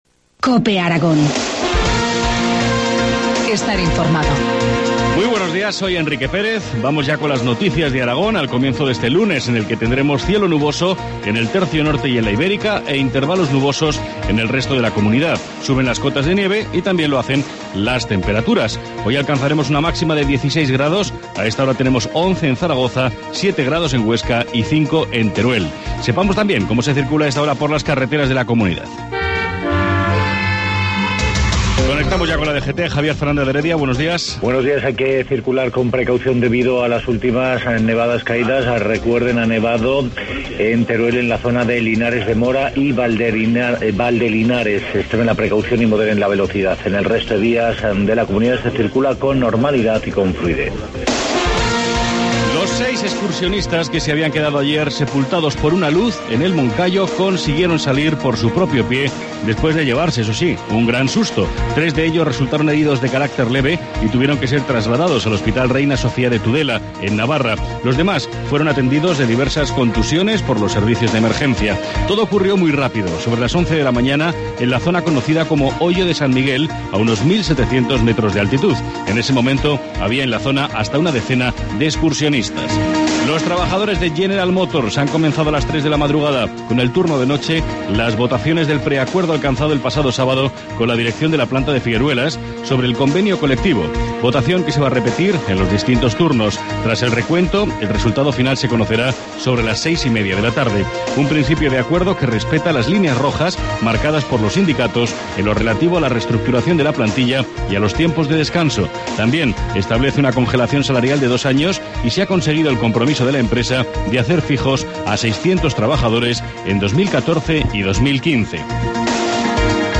Informativo matinal, lunes 8 de abril, 7.25 horas